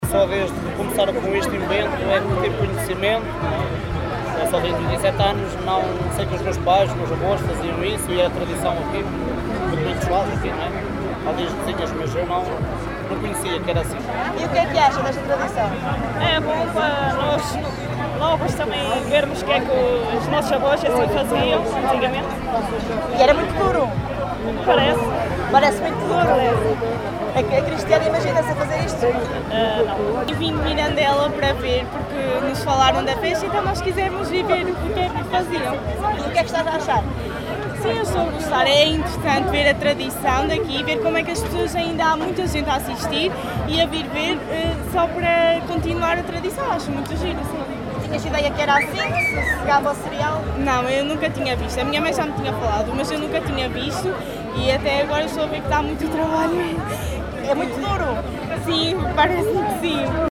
vox-pop-novos.mp3